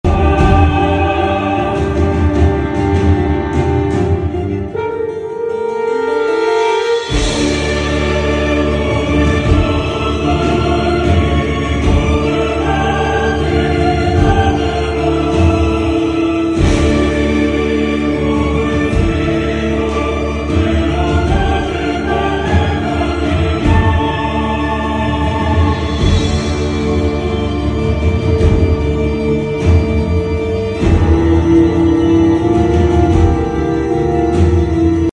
аніме симфонія концерт